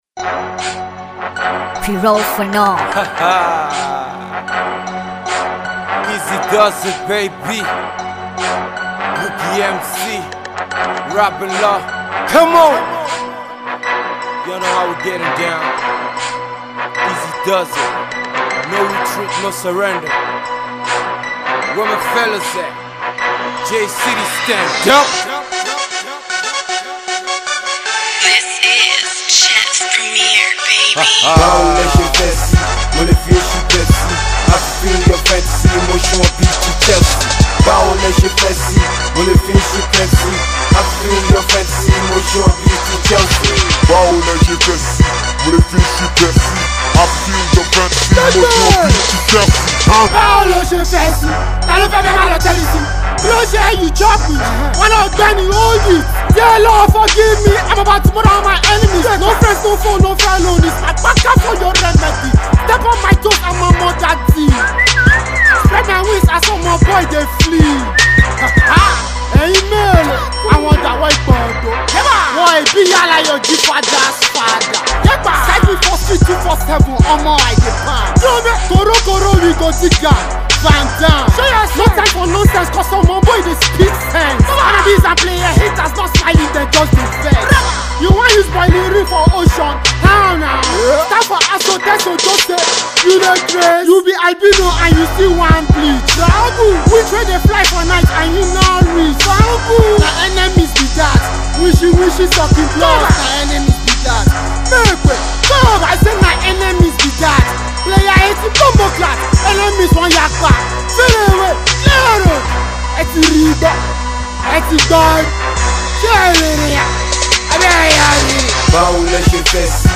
Afro HIPHOP duo